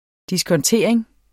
Udtale [ diskʌnˈteɐ̯ˀeŋ ]